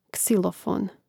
ksȉlofōn ksilofon